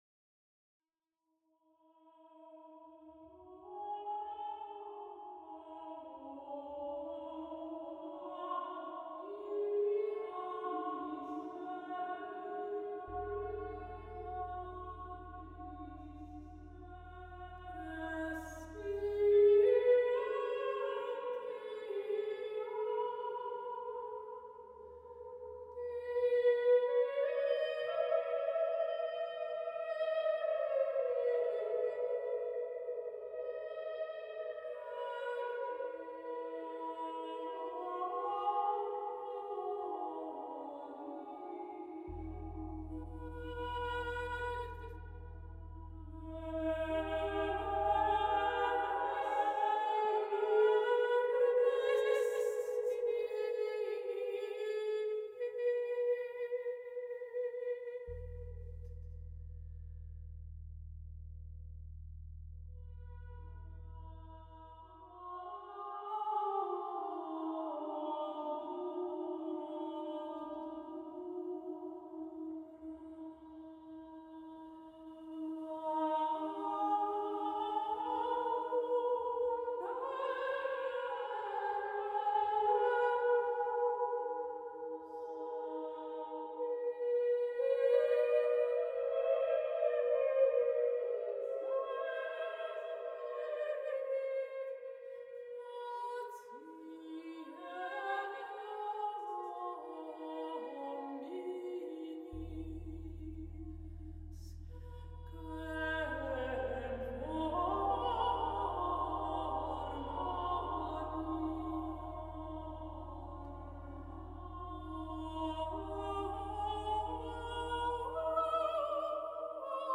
Gesang): Die Antiphon